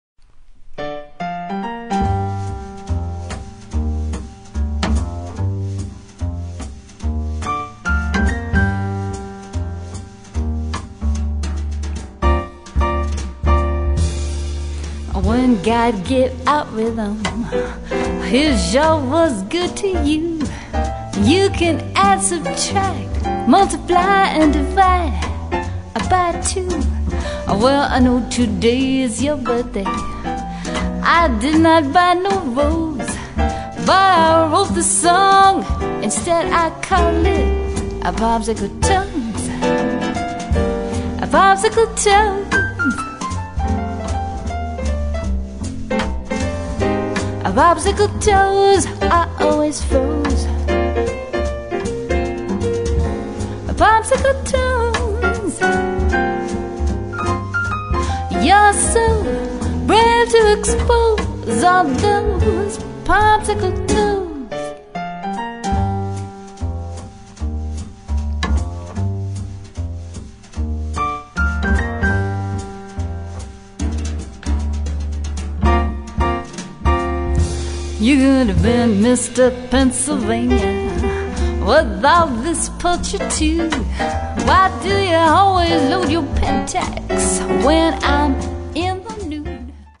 If you are in search of Great Classic Jazz Music you have found the place.
Jazz Standards, Blues, and Big Band Swing
She usually works with piano, bass and drums, but is flexible to your needs and price constraints.